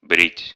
Ääntäminen
IPA: /ˈsxeː.rə(n)/